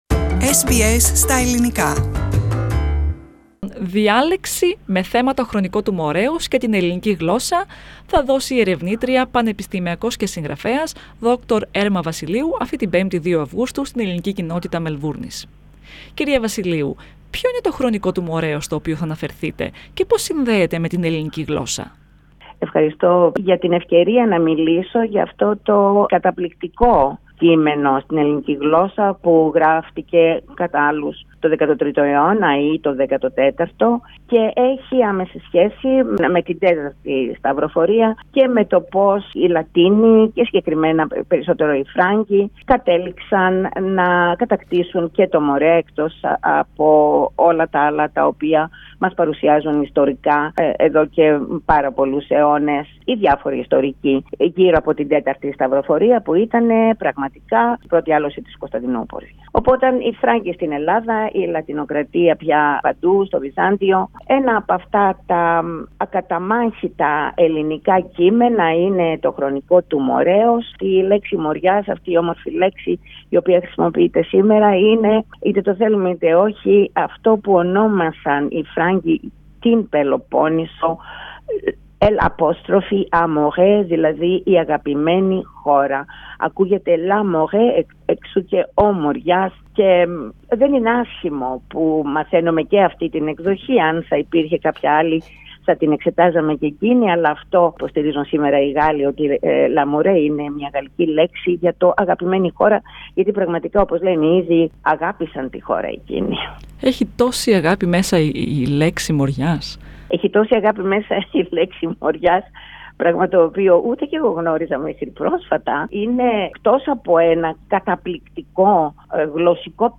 Πατήστε Play στο Podcast που συνοδεύει την κεντρική φωτογραφία για να ακούστε τη συνέντευξη.